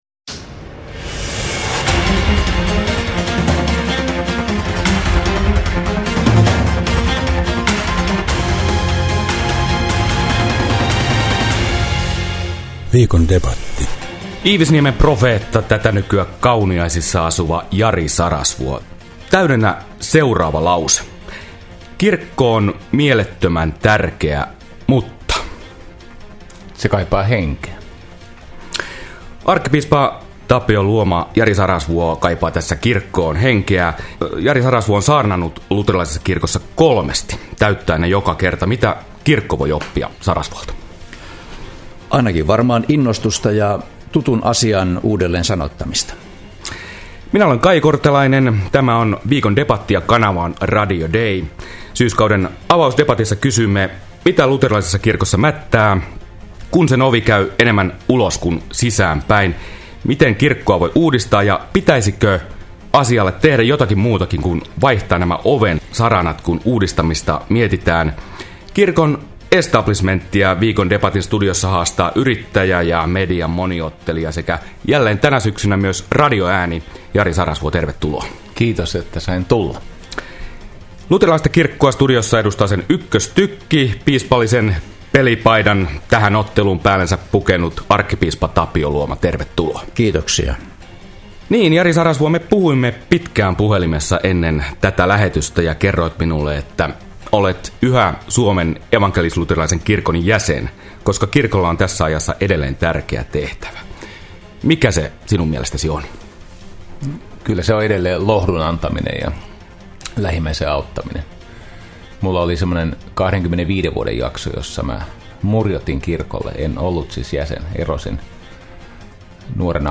Sarasvuon debattiparina studiossa ollut arkkipiispa Tapio luoma keikautti aikeet päälaelleen.
Iivisniemen profeetaksi kutsuttu yrittäjä Jari Sarasvuo haastoi arkkipiispa Tapio Luoman Suomen evankelis-luterilaisen kirkon tulevaisuutta käsittelevään radiokeskusteluun, joka kuultiin Radio Dein aamulähetyksessä tänään keskiviikkona.